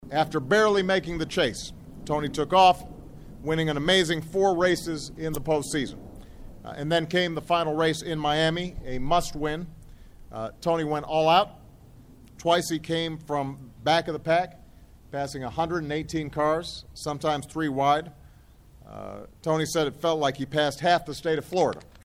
WASHINGTON, DC – APRIL 17: U.S. President Barack Obama (R), stands with NASCAR Champion Tony Stewart (L) during an event on the South Lawn, April 17, 2012 at the White House in Washington, DC.